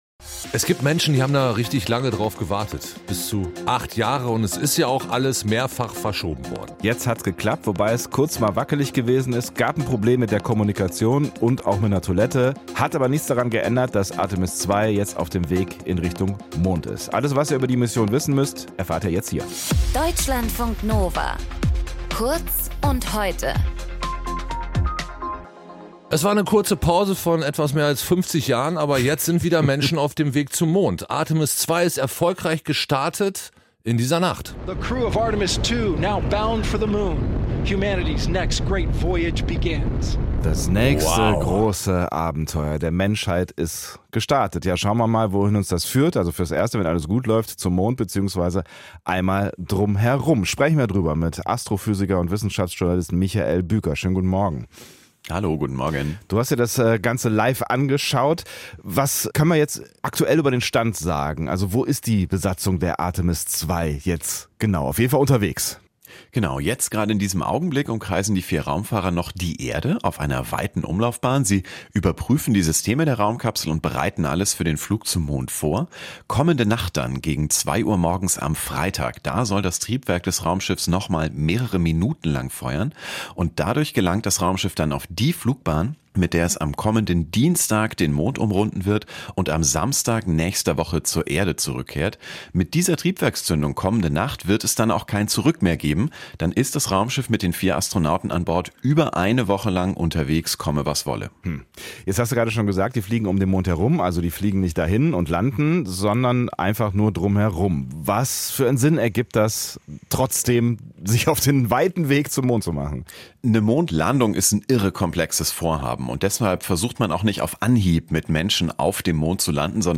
In dieser Folge mit:
Astrophysiker